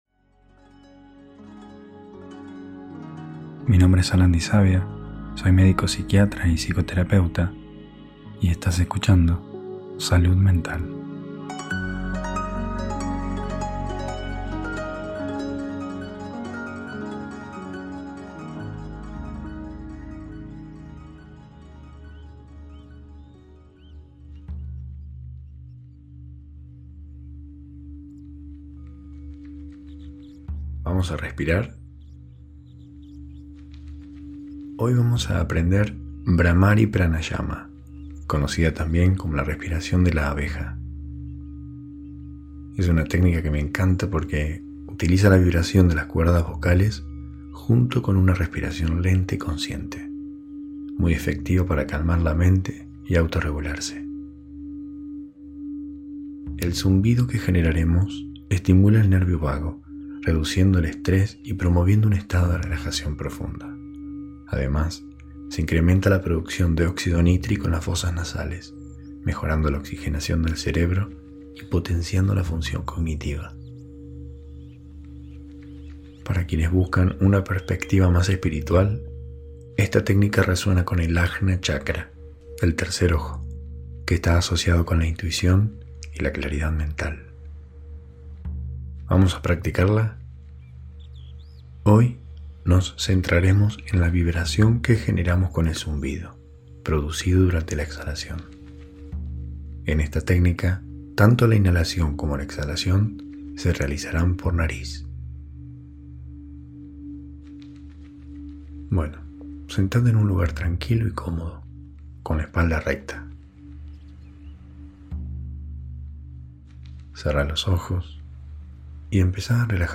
Una técnica que utiliza la vibración del sonido para calmar la mente, reducir el estrés y armonizar el cuerpo. Ideal para relajarte, autoregularte y conectar con uno mismo.